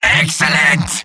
Robot-filtered lines from MvM. This is an audio clip from the game Team Fortress 2 .
{{AudioTF2}} Category:Medic Robot audio responses You cannot overwrite this file.
Medic_mvm_cheers01.mp3